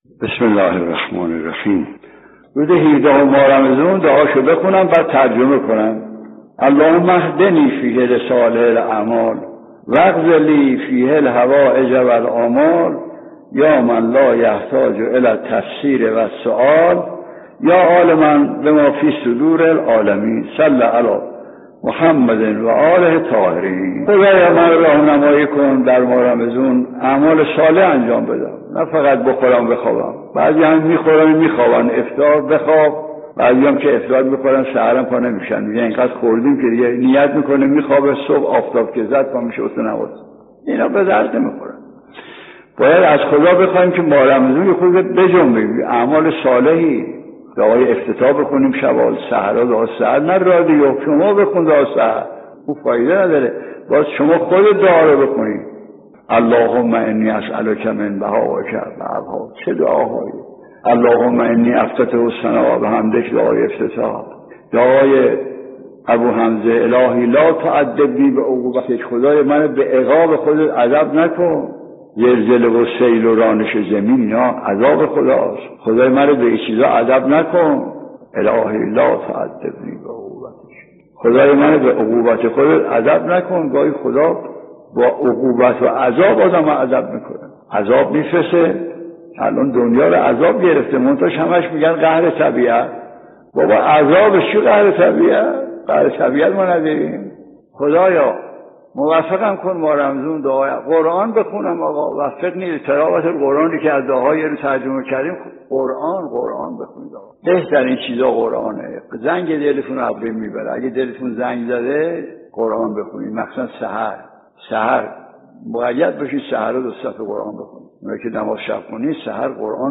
در یکی از سخنرانی‌های خود به «شرح دعای روز هفدهم ماه مبارک رمضان» پرداختند که تقدیم شما فرهیختگان می‌شود.